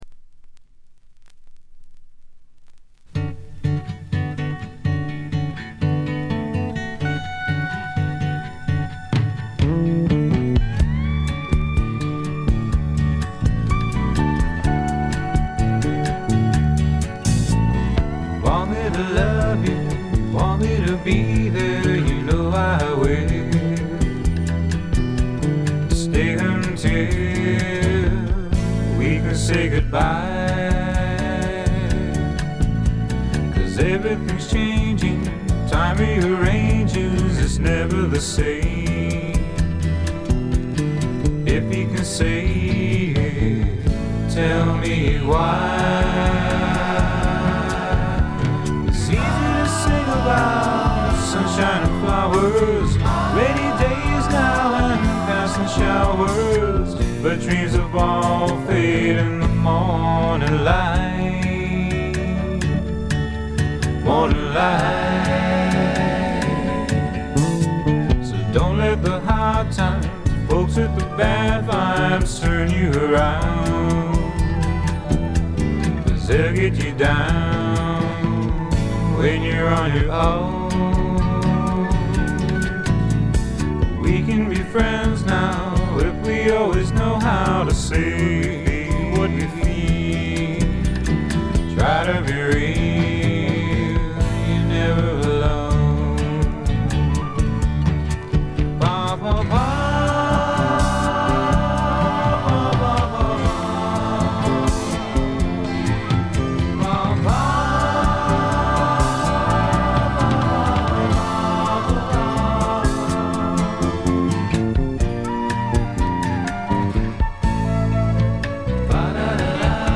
部分試聴ですがわずかなノイズ感のみ。
潮風に乗せたちょっとフォーキーなAORといったおもむきが心地よいです。
試聴曲は現品からの取り込み音源です。